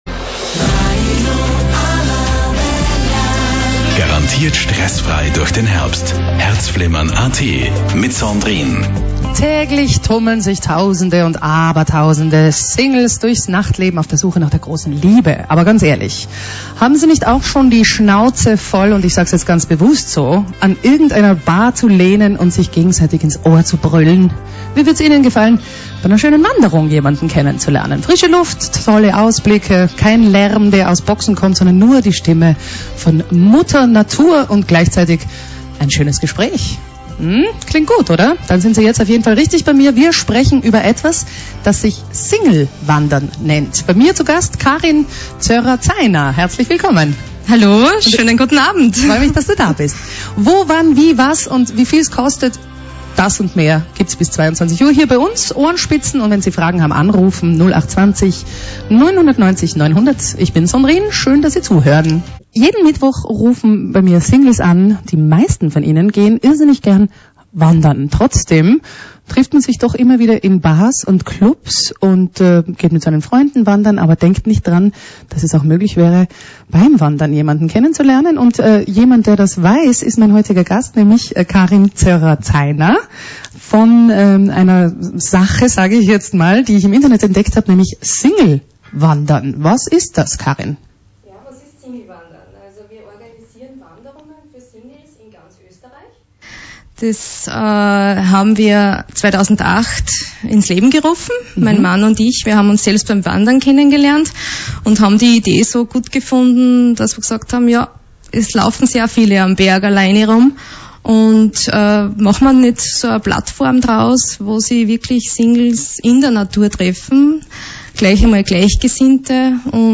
Herzfilmmern - Radio Arabella SingleWandern Interview